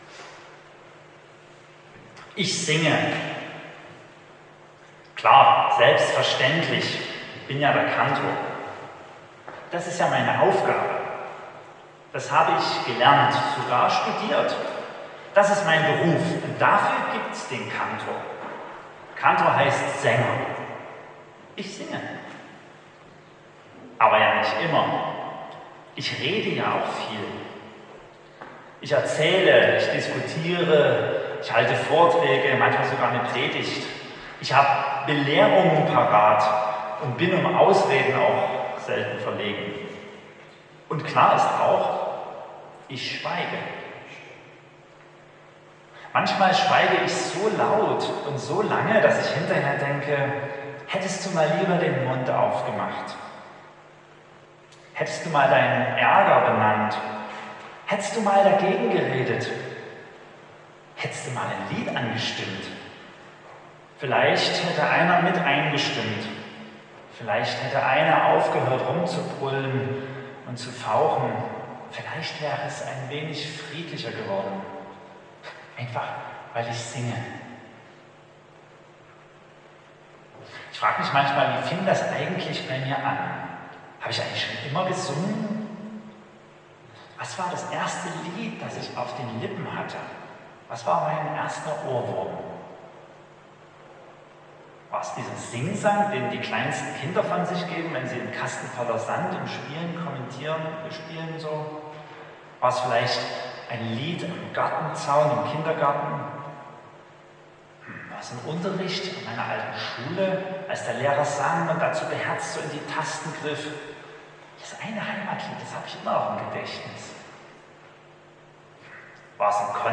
Predigt und Aufzeichnungen